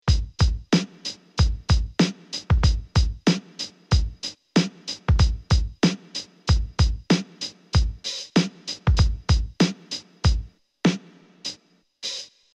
Like the SP-12, it used downsampling for memory efficiency, giving it a unique crunchy yet fat sound that’s still popular today.
Akai MPC60